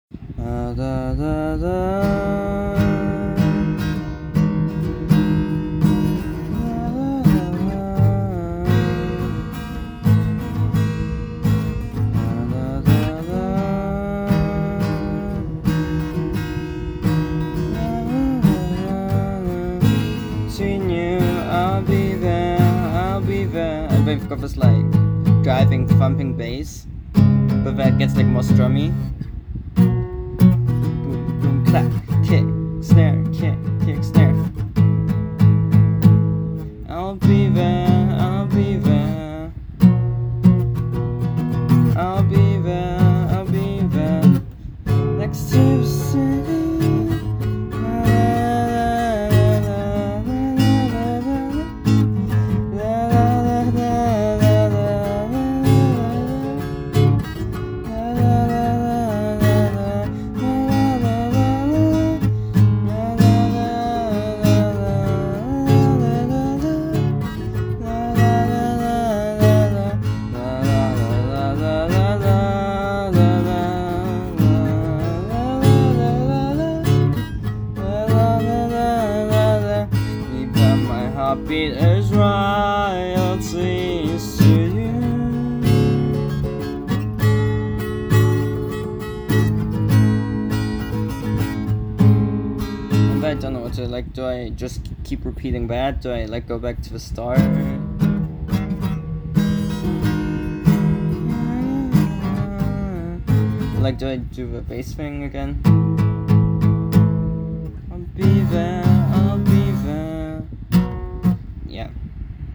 been getting back into playing guitar, here's a sketch i made up on the worlds most untunable one